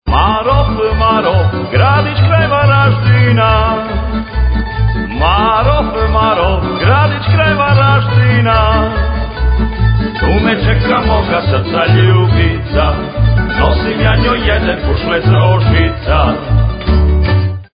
Audio zapis pjesama u izvođenju društva. Cd je snimljen u prostorijama Osnovne škole Novi Marof tijekom ljeta 2002. godine.
Već tri dana (narodna)